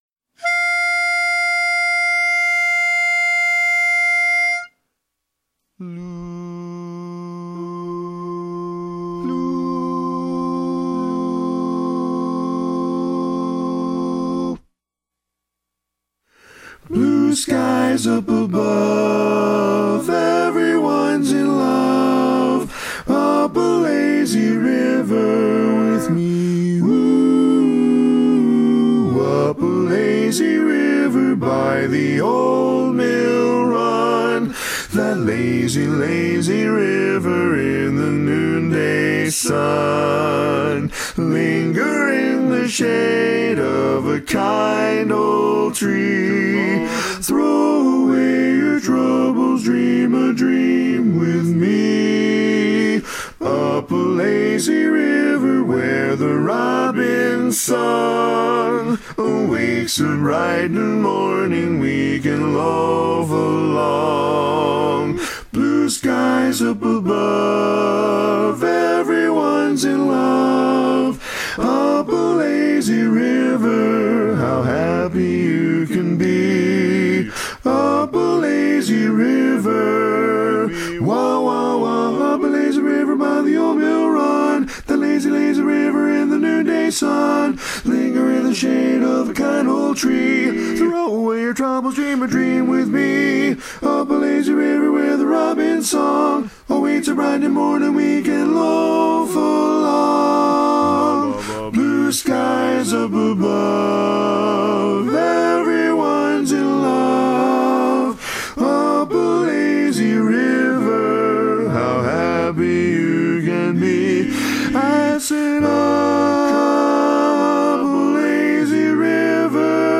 Ballad
F Major
Bari